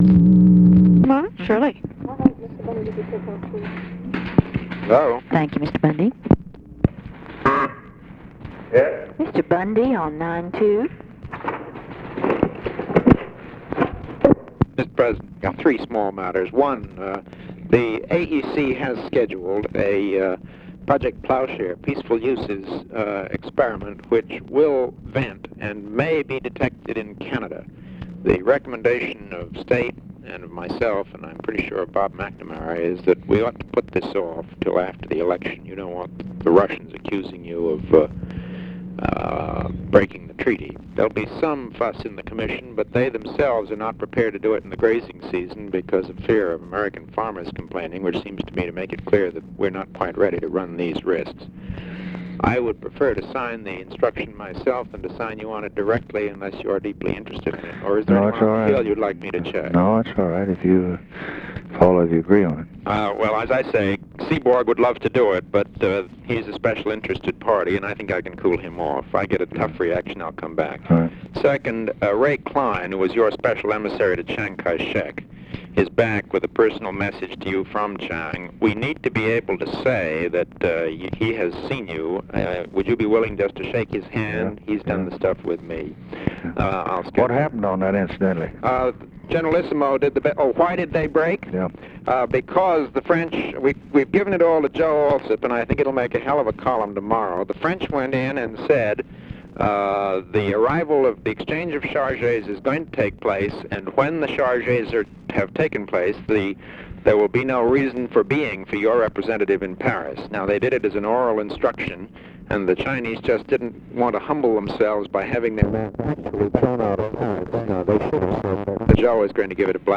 Conversation with MCGEORGE BUNDY, February 11, 1964
Secret White House Tapes